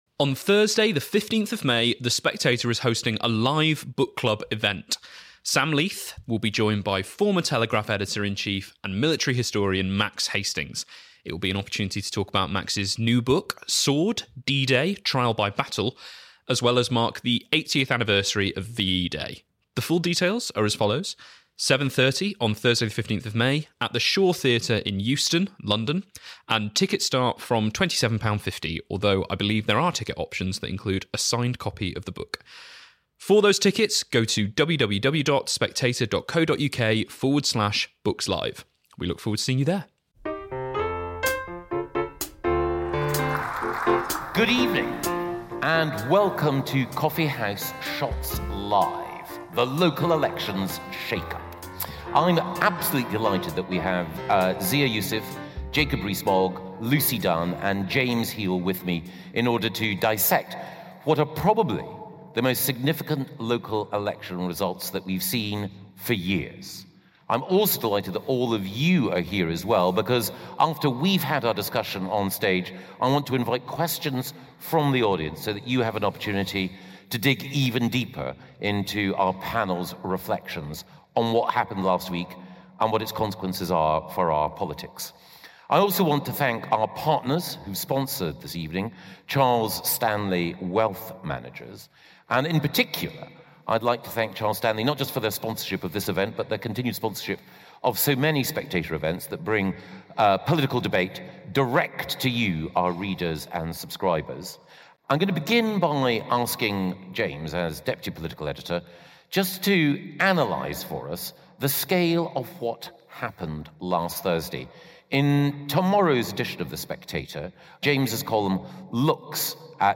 This podcast was originally recorded live at the Emmanuel Centre in Westminster on Wednesday 7 May.